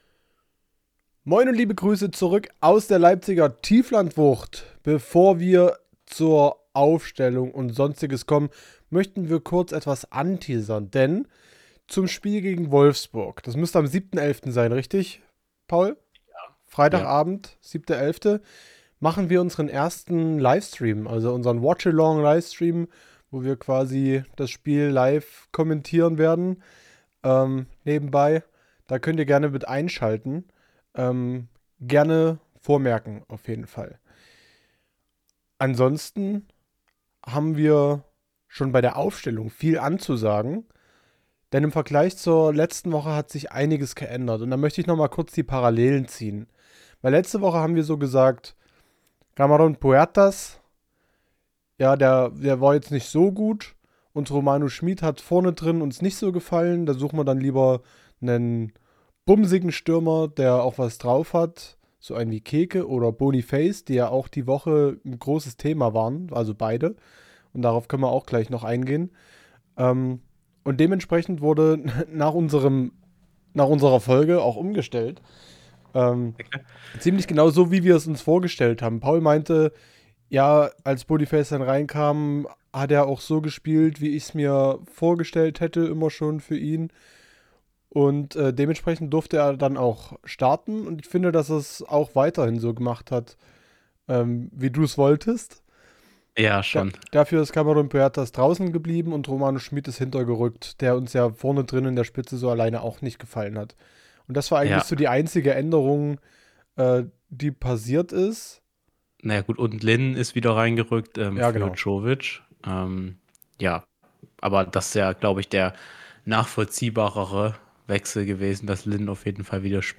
Unser größtes Bedürfnis ist es über Werder zu reden und am allerliebsten machen wir das mit euch! Wenn euch dieser Werder-Talk gefällt, seid ihr herzlich dazu eingeladen uns hier zu abonnieren....